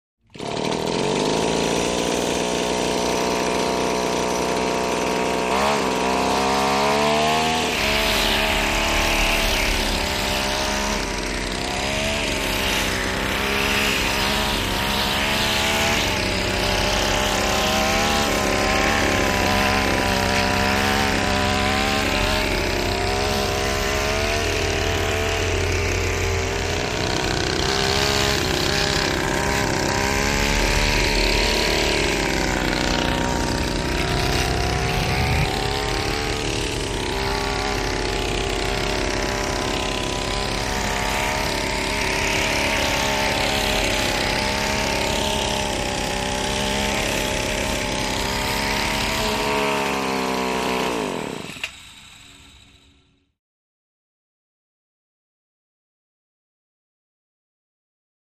Model Airplane, Close To Medium Perspective; Start And Run With Movement, Off At End.